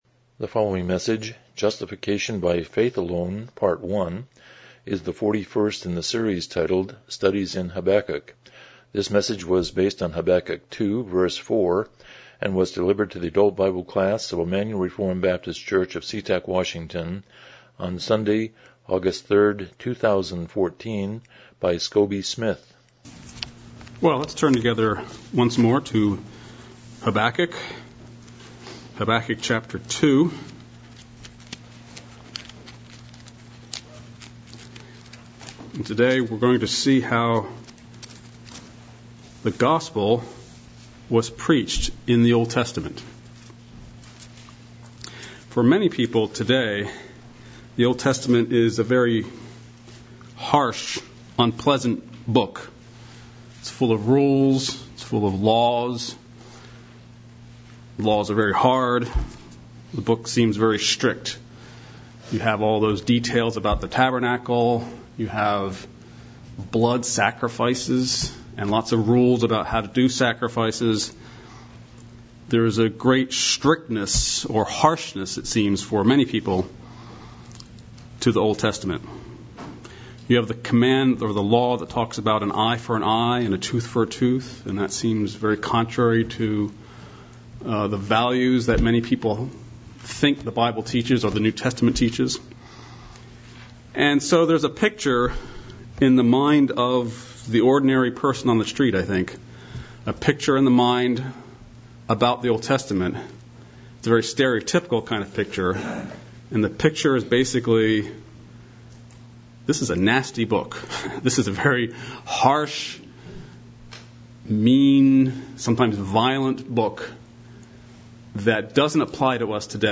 Studies in Habakkuk Passage: Habakkuk 2:4 Service Type: Sunday School « Everything Beautiful in its Time 60 The Parable of the Sower